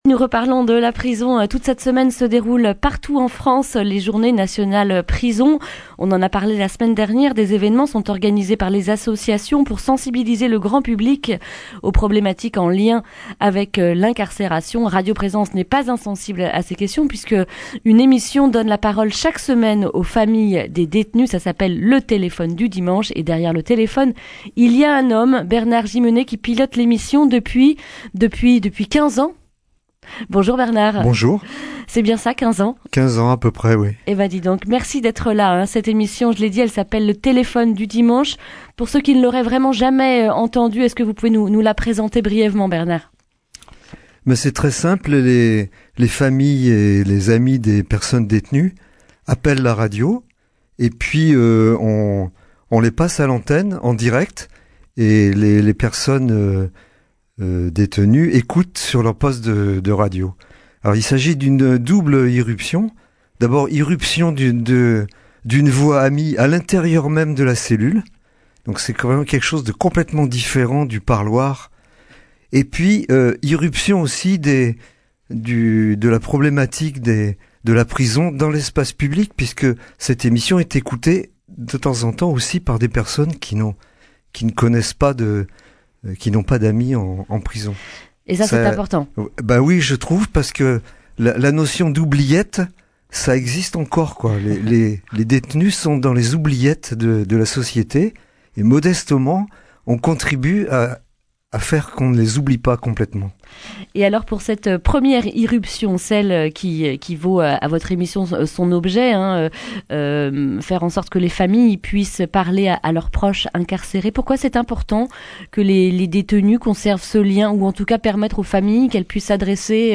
lundi 25 novembre 2019 Le grand entretien Durée 10 min